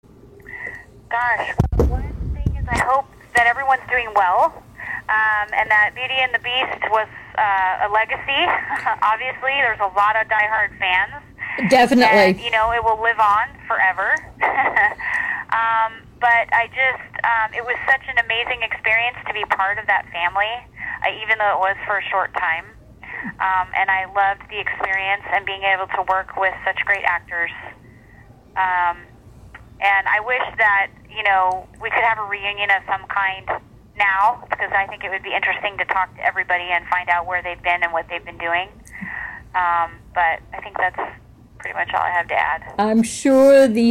Audio Greeting